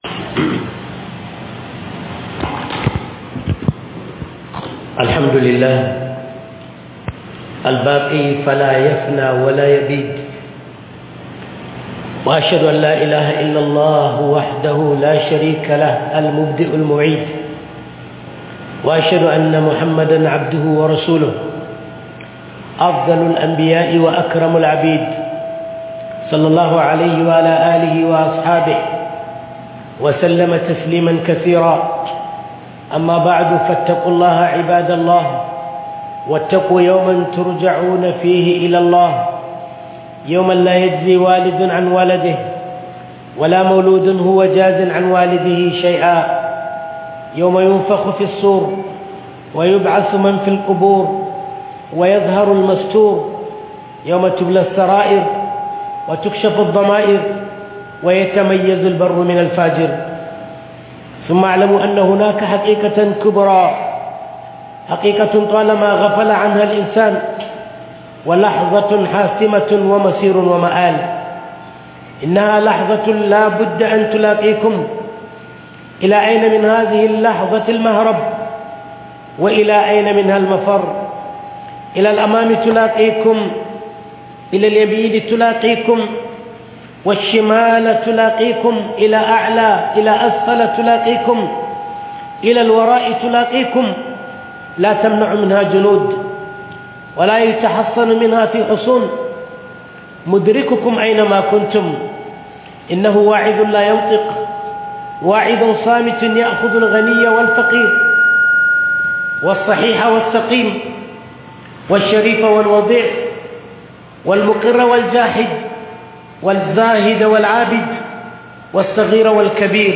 MUTUWA BABAN WA'AZI - Huduba by Prof. Mansur Ibrahim Sokoto